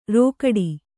♪ rōkaḍi